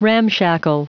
Prononciation du mot ramshackle en anglais (fichier audio)
Prononciation du mot : ramshackle